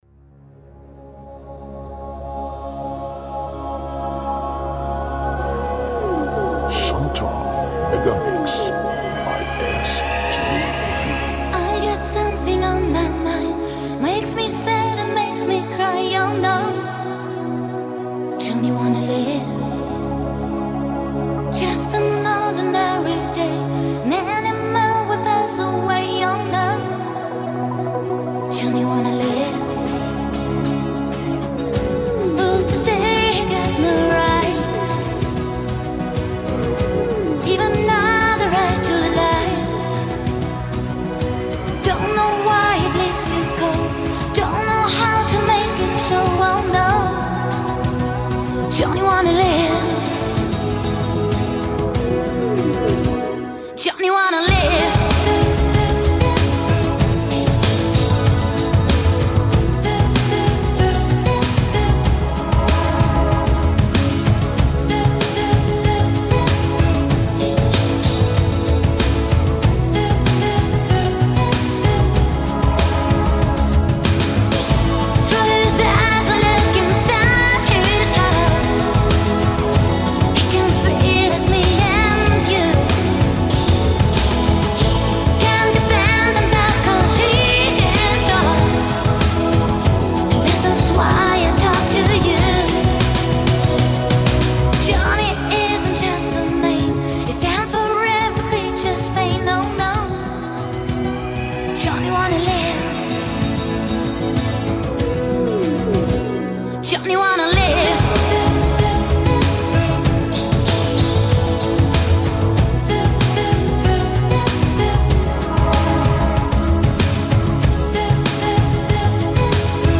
Music Non-Stop, Mixes & Megamixes